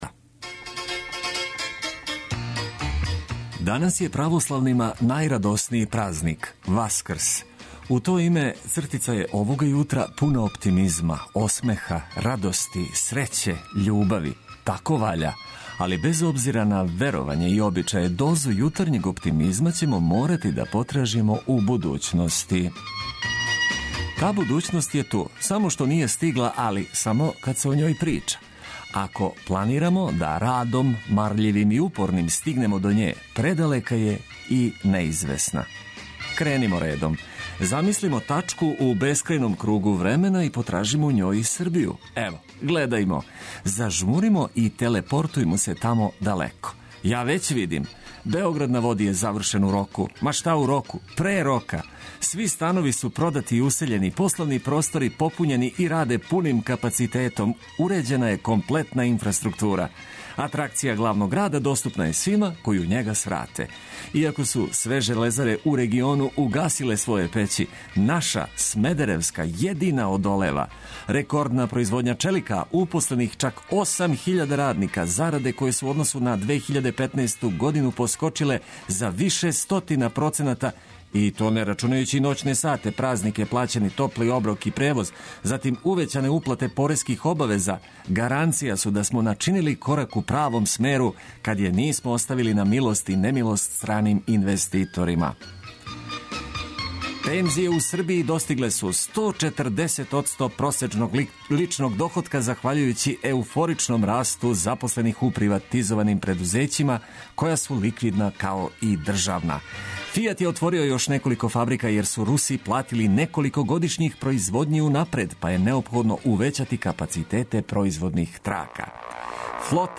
Слушаћемо адекватну музику за лепше празнично расположење.